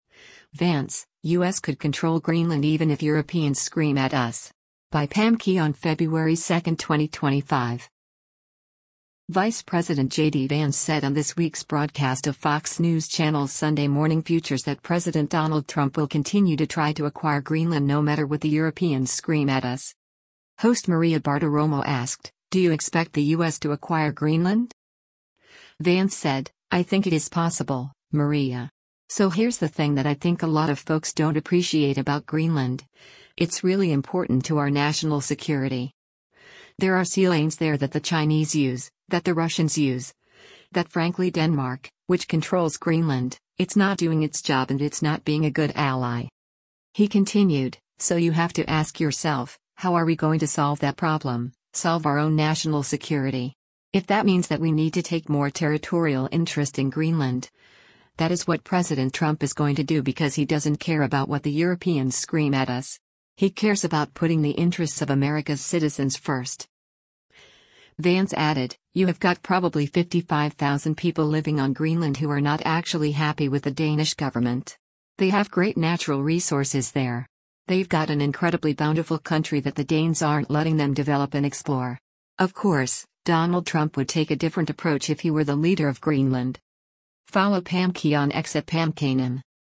Vice President JD Vance said on this week’s broadcast of Fox News Channel’s “Sunday Morning Futures” that President Donald Trump will continue to try to acquire Greenland no matter “what the Europeans scream at us.”
Host Maria Bartiromo asked, “Do you expect the U.S. to acquire Greenland?”